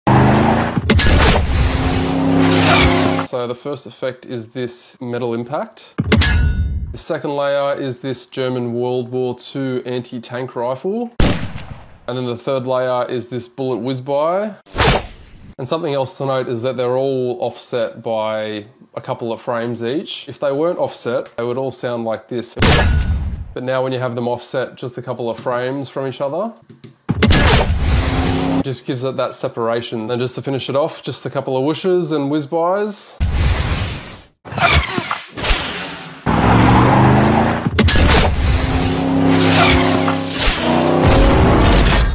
🤖 Sound re design breakdown "Transformers" sound effects free download